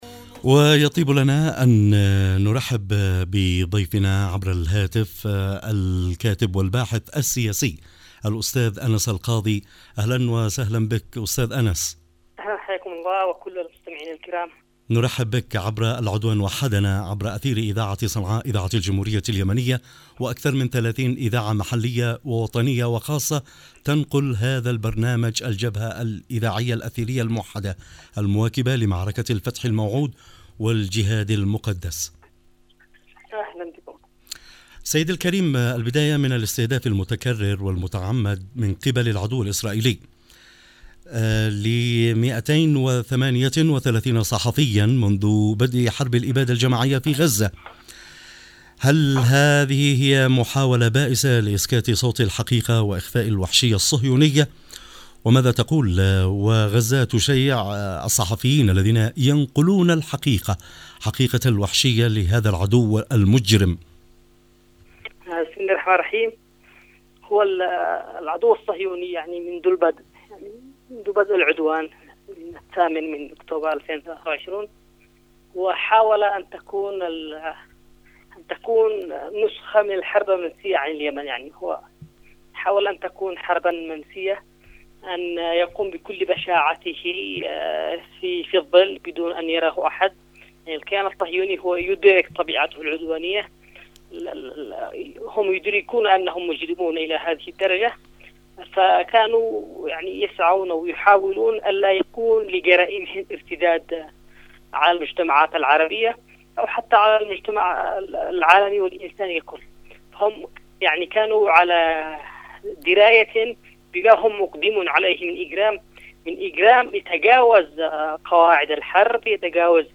☎ لقاء عبر الهاتف لبرنامج العدوان وحدنا عبر إذاعة صنعاء البرنامج العام والإذاعات الوطنية المرتبطة مع